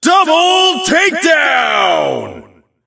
doubletakedown_vo_01.ogg